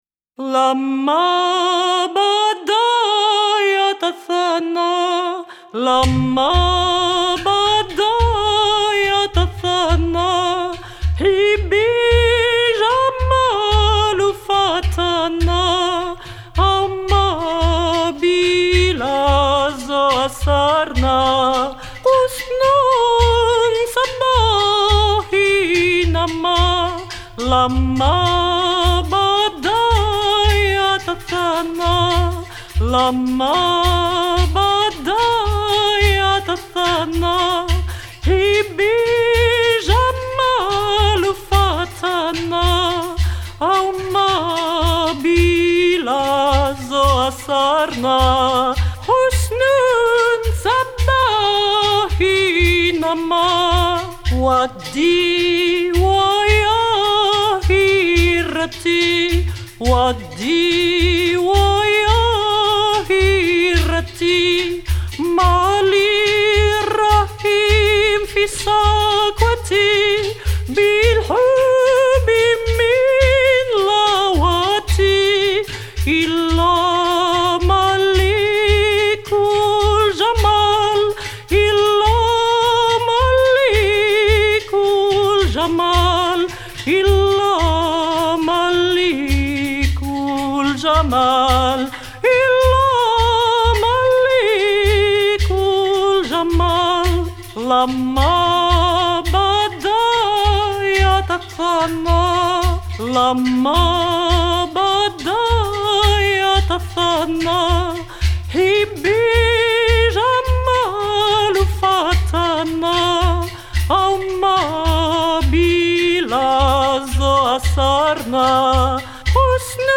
- Mezzo-soprano Soprano